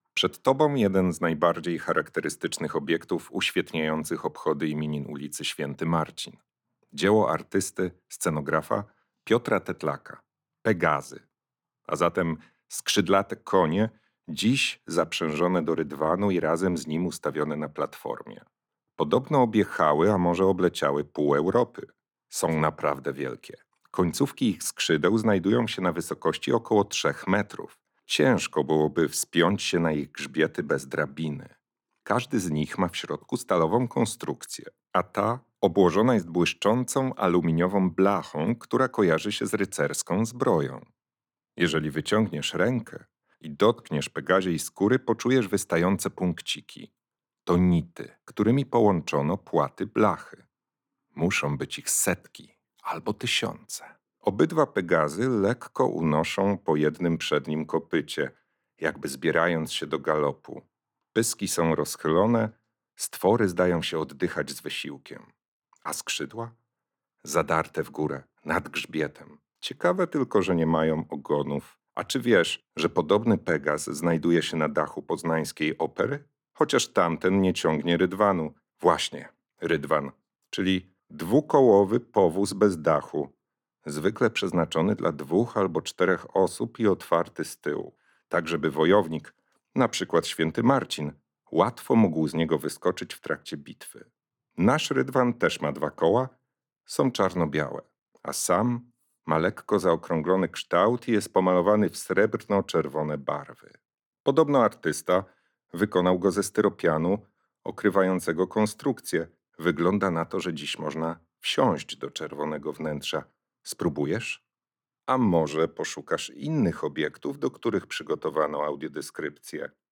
Pegazy – audiodeskrypcja